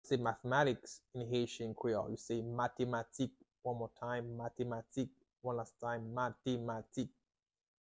How to say "Mathematics" in Haitian Creole - "Matematik" pronunciation by a native Haitian tutor
“Matematik” Pronunciation in Haitian Creole by a native Haitian can be heard in the audio here or in the video below:
How-to-say-Mathematics-in-Haitian-Creole-Matematik-pronunciation-by-a-native-Haitian-tutor.mp3